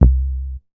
MoogYubby 003.WAV